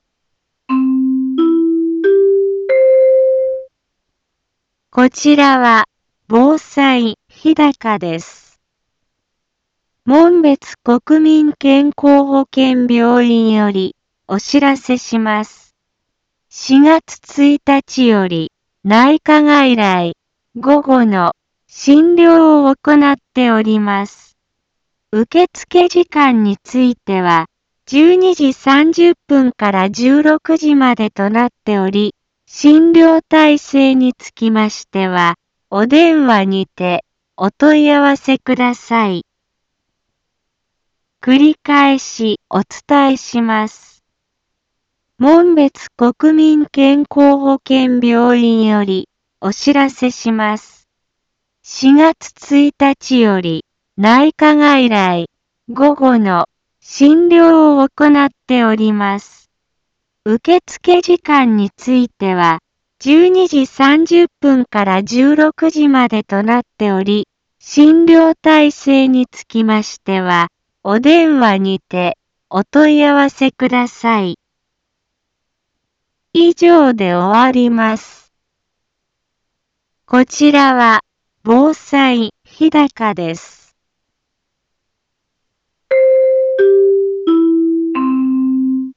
一般放送情報
BO-SAI navi Back Home 一般放送情報 音声放送 再生 一般放送情報 登録日時：2019-04-04 10:03:17 タイトル：内科外来午後の診療について インフォメーション： 門別国民健康保険病院より、お知らせします。 4月1日より、内科外来、午後の、診療をおこなっております。